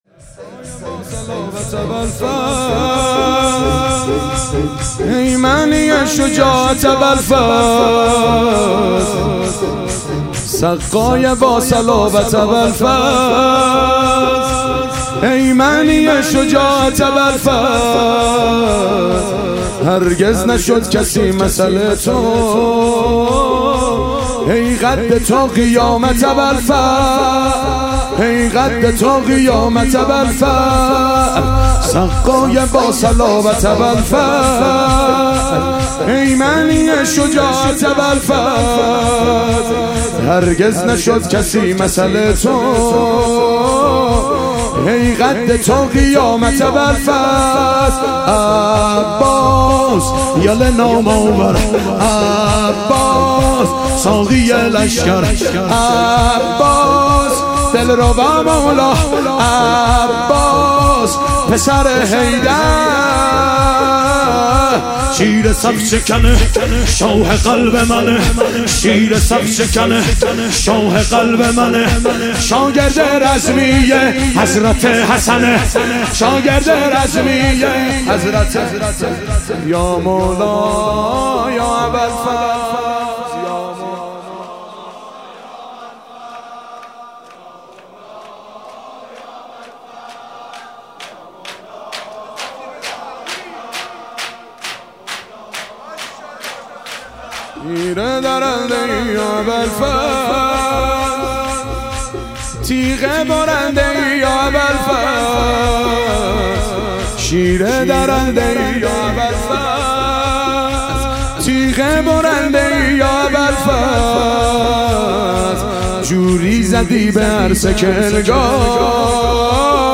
شش امام حسین علیه السلام - شور